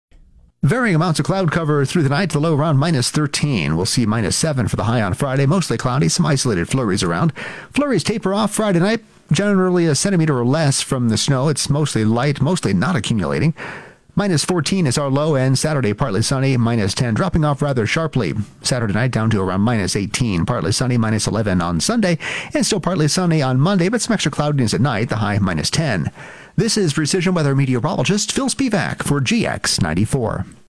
GX94 5:30pm Precision Weather Forecast – November 27, 2025